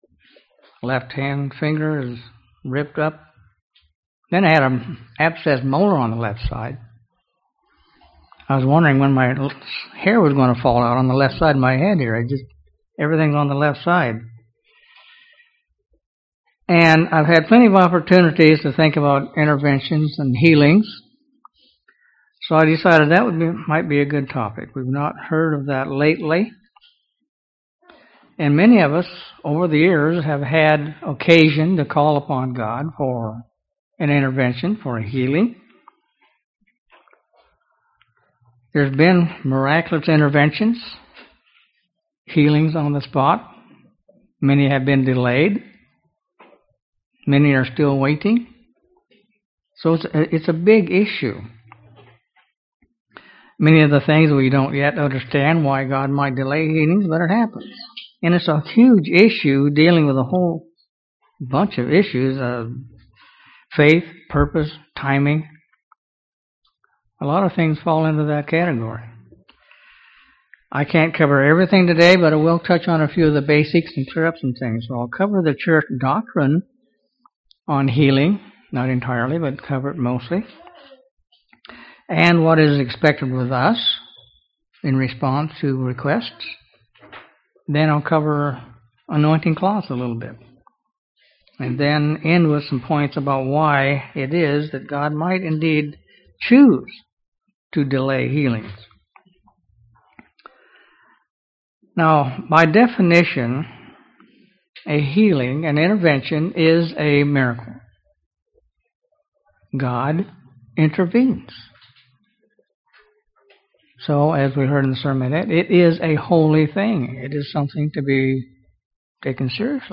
This sermon discusses the Church's doctrine on healing giving a number of Biblical examples.